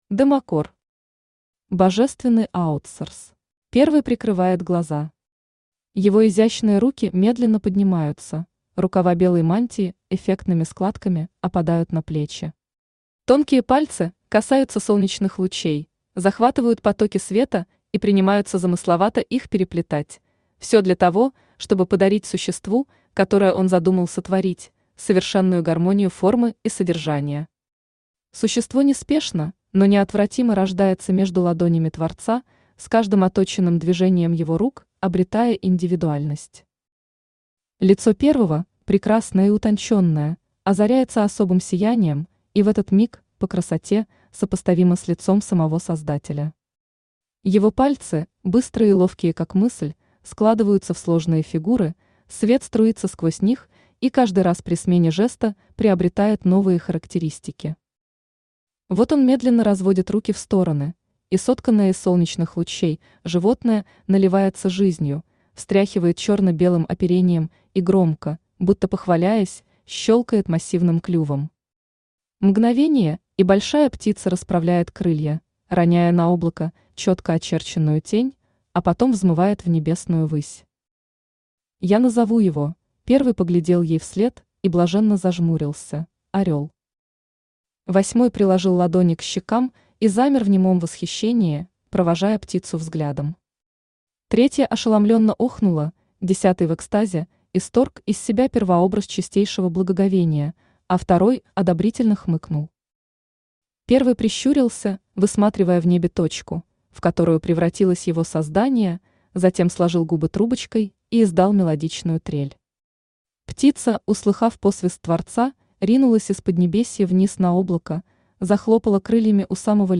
Аудиокнига Божественный аутсорс | Библиотека аудиокниг
Aудиокнига Божественный аутсорс Автор Demaсawr Читает аудиокнигу Авточтец ЛитРес.